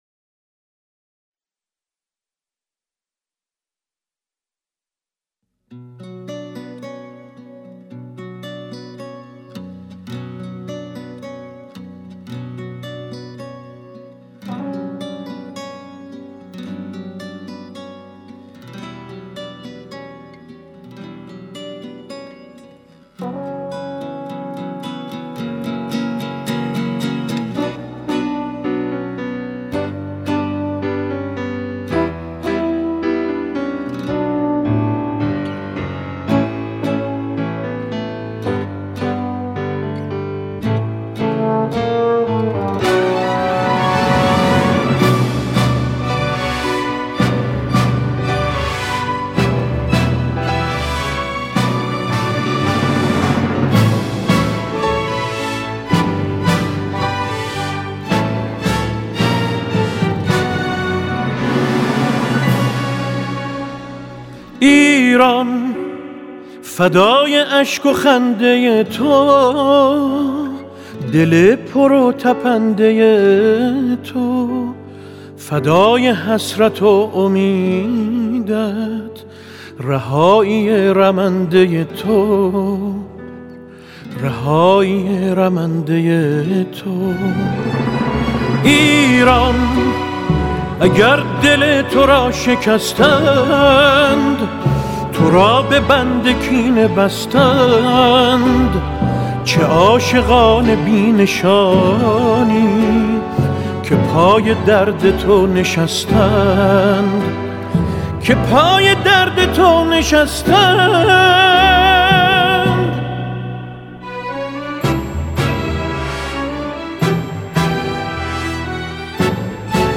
خواننده موسیقی سنتی